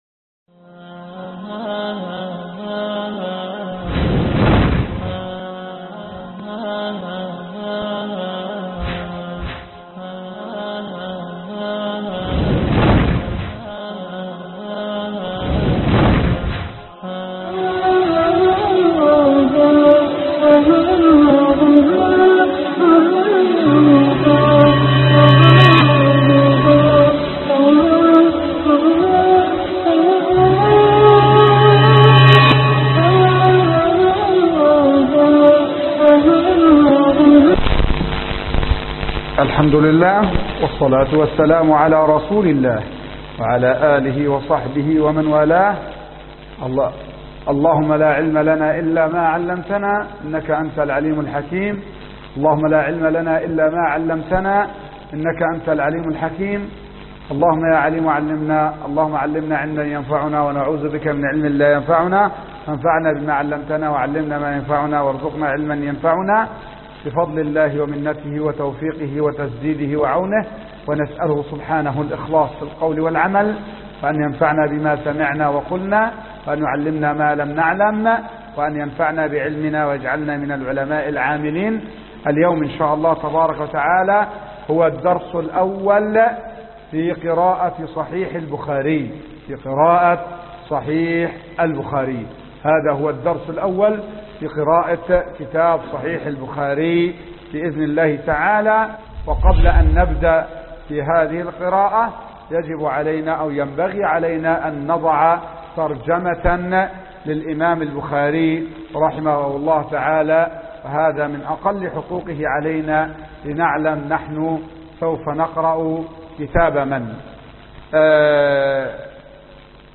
الدرس الأول من قراءة صحيح البخاري